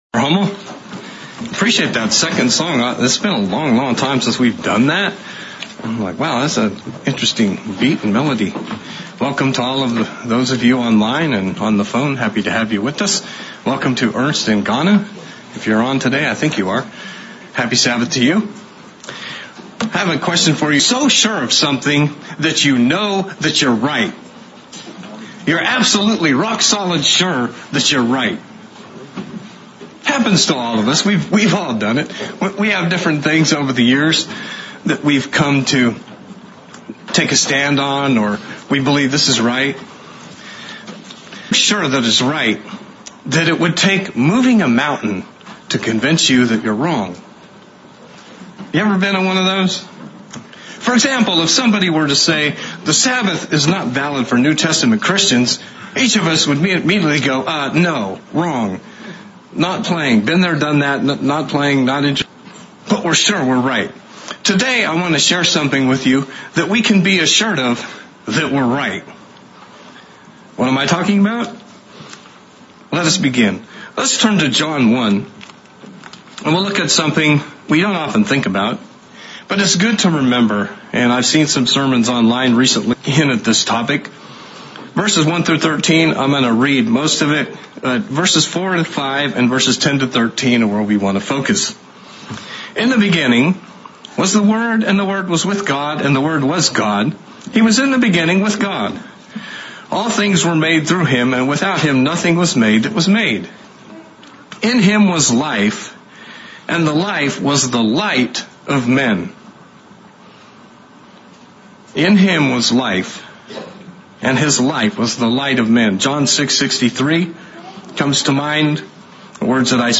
Sermonette looking at scriptures from John 1 and I Jn 1 that John gave us about walking in light and the 6 small lessons John gives us on this vital subject. 3 from John 1 and 3 from 1st John 1.